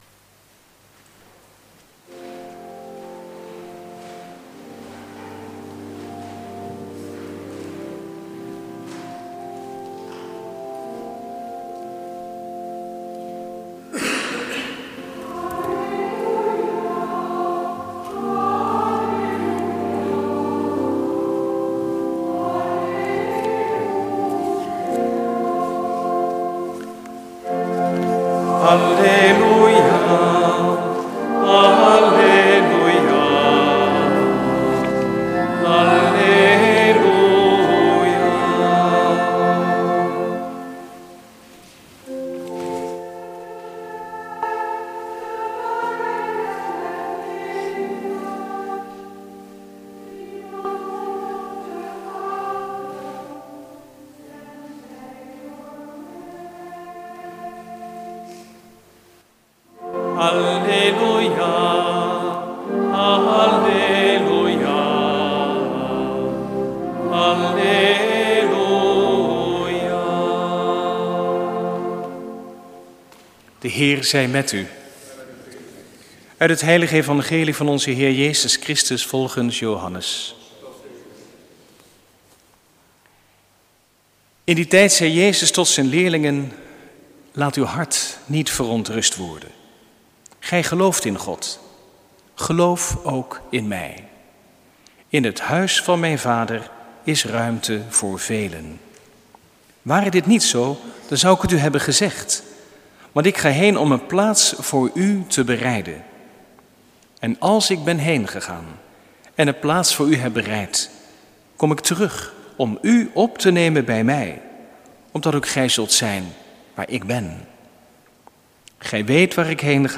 Preek op de gedachtenis van Allerzielen, 2 november 2012 | Hagenpreken
Viering beluisteren vanuit De Goede Herder te Wassenaar (MP3)